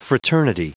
Prononciation du mot fraternity en anglais (fichier audio)
Prononciation du mot : fraternity